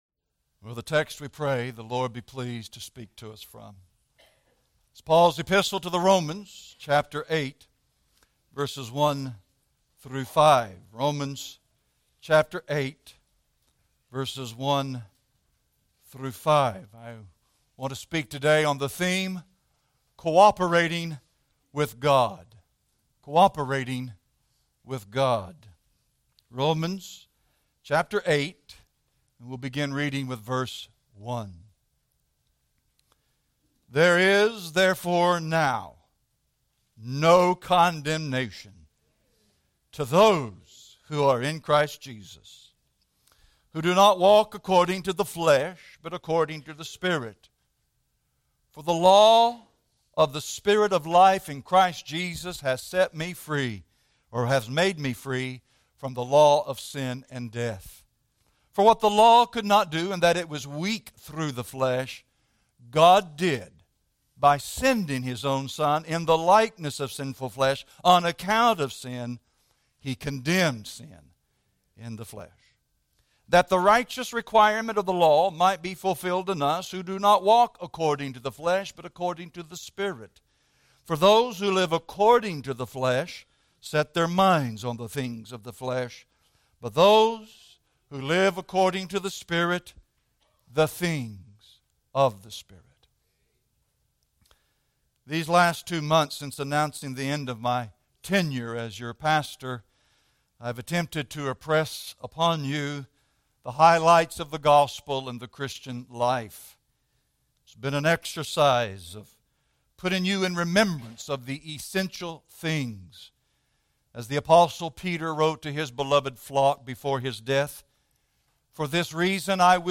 Cooperating with God - A Farewell Sermon | Real Truth Matters